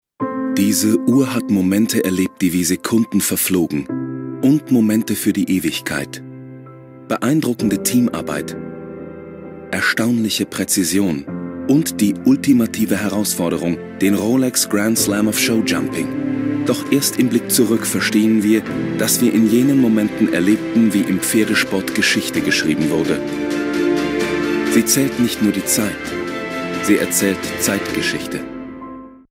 sehr variabel, dunkel, sonor, souverän
Mittel minus (25-45)
Commercial (Werbung)
Kölsch, Rheinisch, Ruhrgebiet